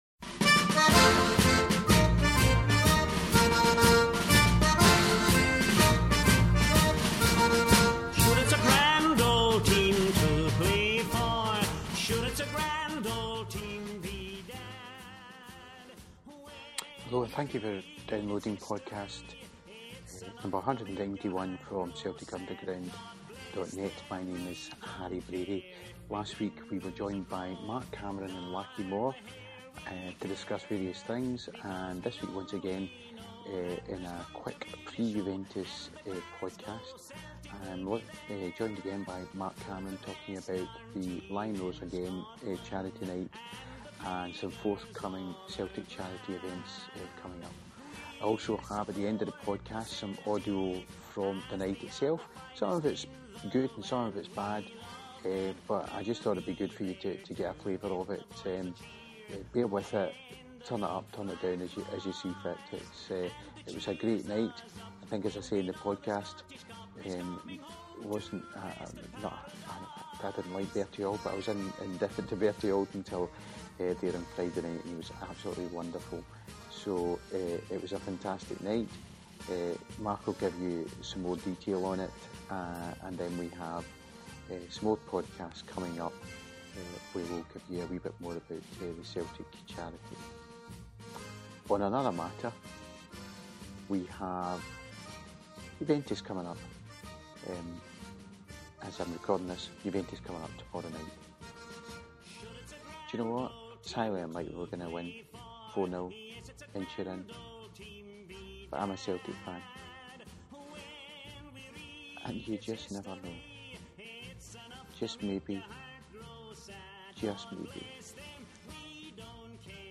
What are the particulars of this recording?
The bhoys then briefly touch on Juve game. There is audio from the night with some of the Q&A'S. This comes and goes a bit so be patient.